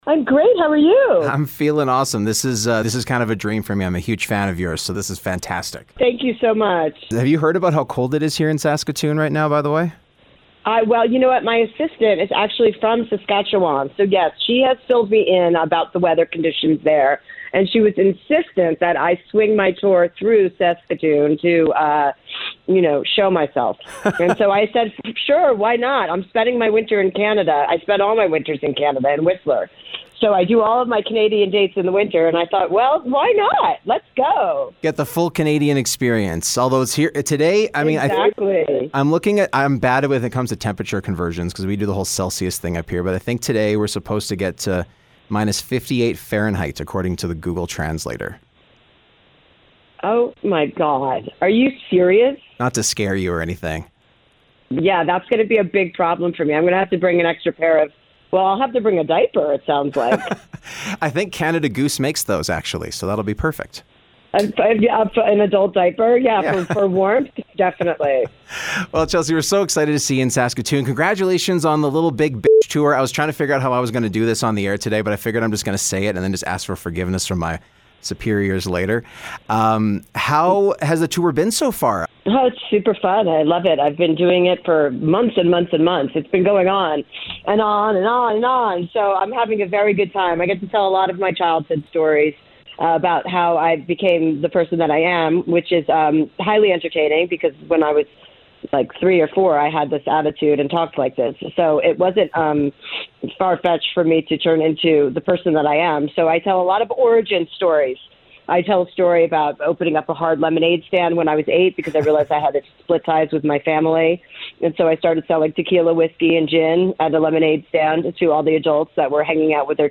Interview: Chelsea Handler